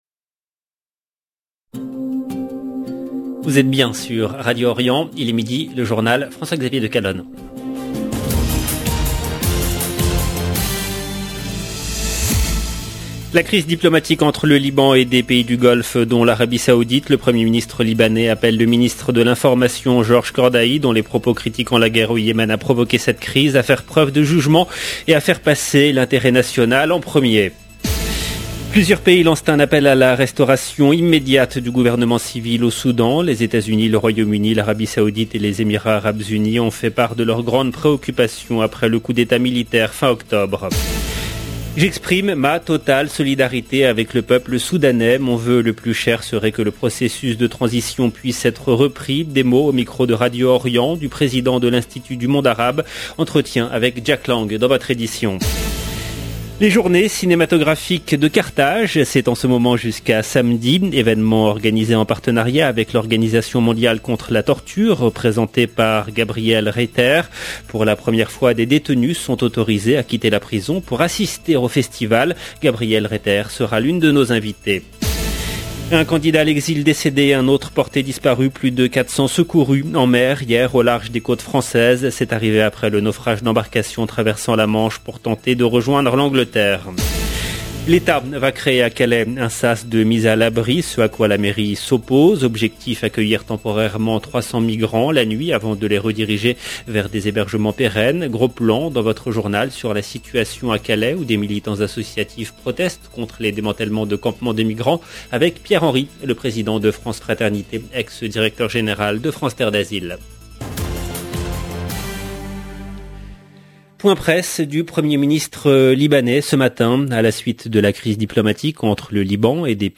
Entretien avec Jack lang dans votre édition.